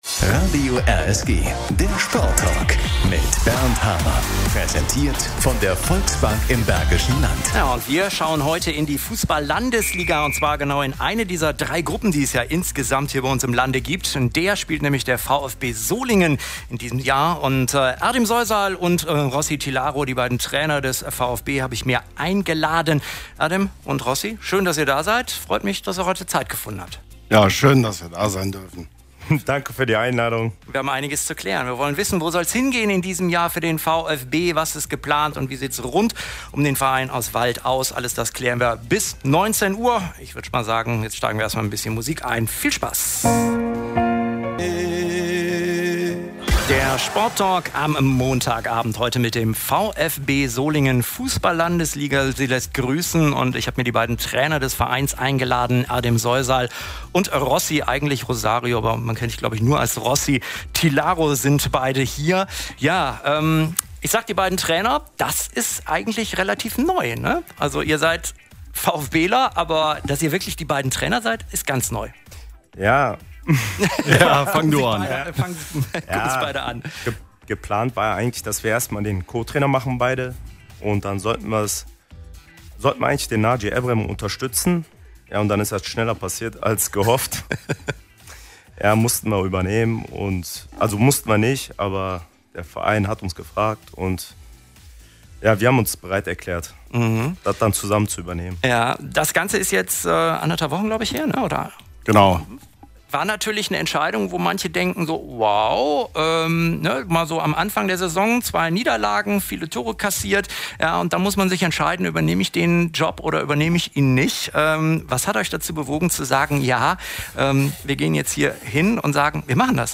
Bei uns sprechen sie über ihre Beweggründe und Ziele (Sendung 28.09.2020):